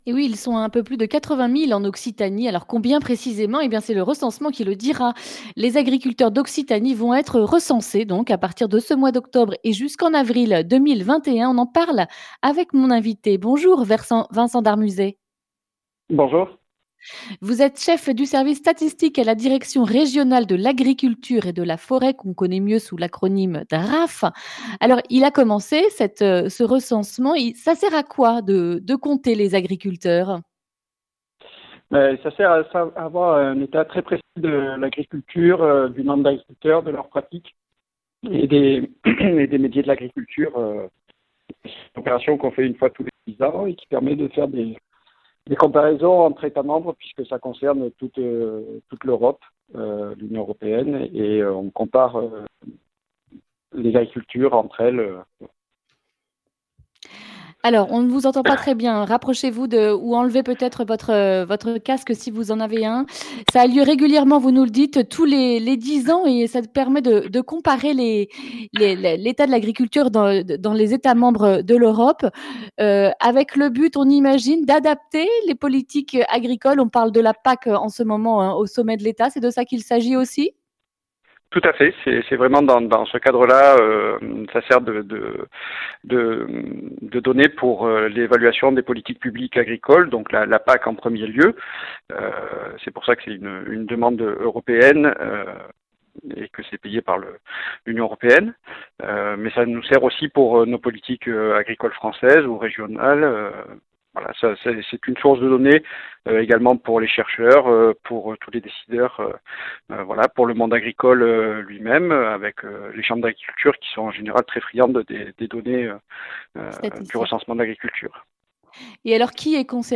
mercredi 21 octobre 2020 Le grand entretien Durée 11 min